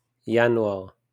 wymowa:
IPA/januaʁ/; polska: januar